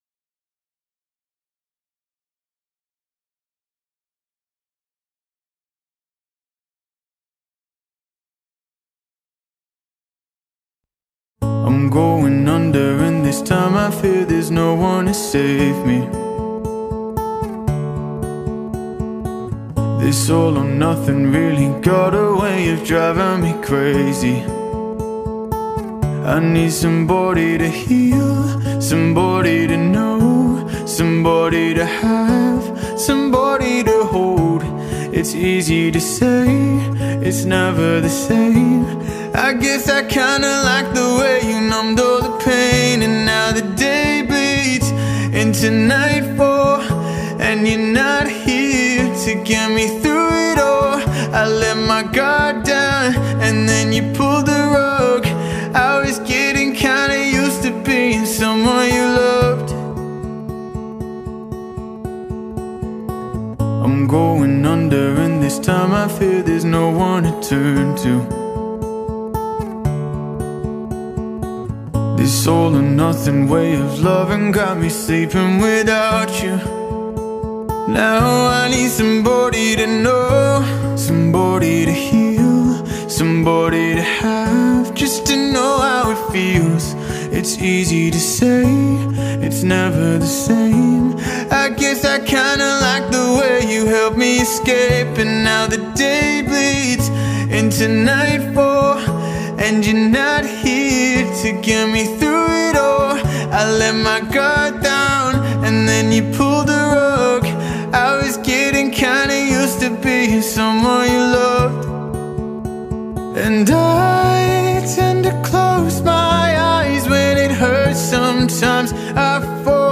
Vocals | Guitar | Looping | DJ